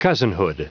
Prononciation du mot cousinhood en anglais (fichier audio)
Prononciation du mot : cousinhood